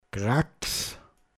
Pinzgauer Mundart Lexikon